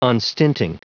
Prononciation du mot unstinting en anglais (fichier audio)
Prononciation du mot : unstinting